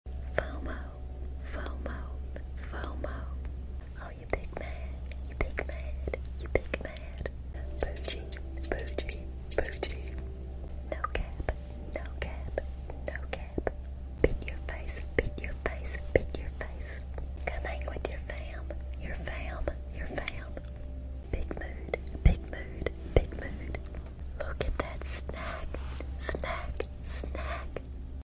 ASMR Gen Z Trigger Words sound effects free download